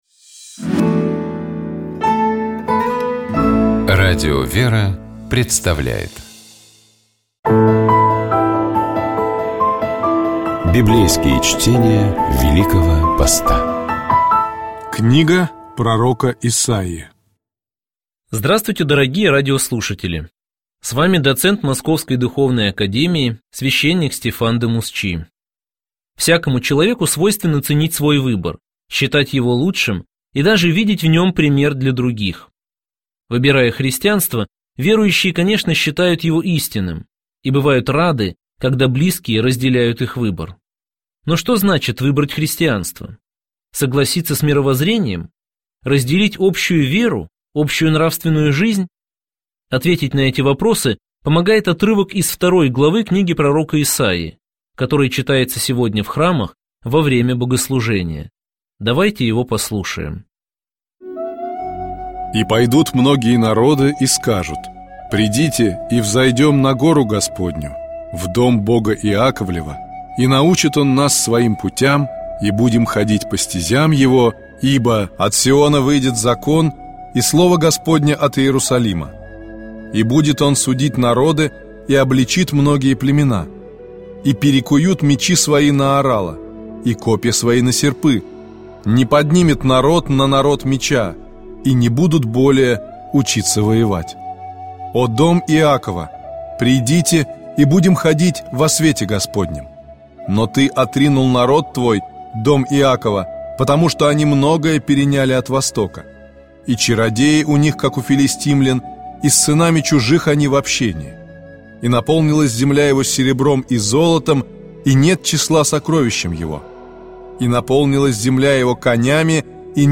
Библейские чтения
Читает и комментирует